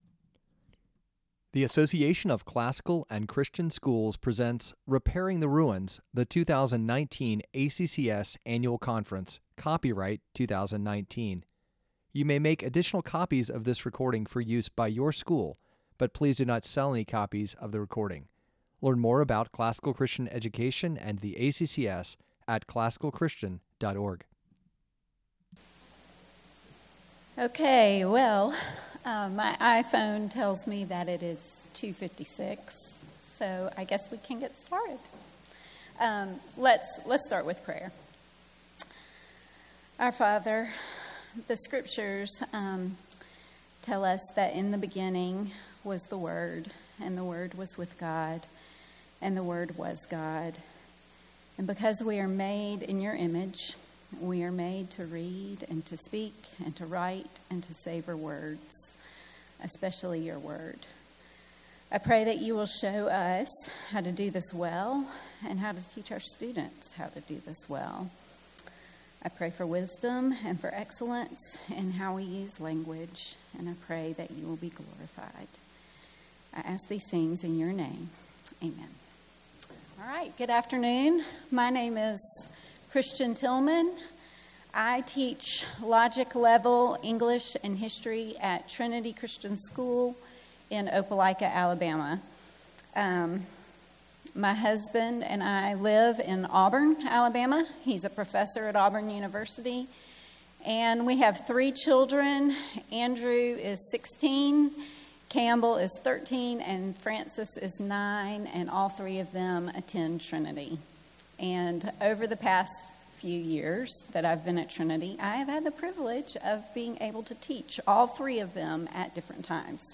2019 Workshop Talk | 47:51 | K-6, Rhetoric & Composition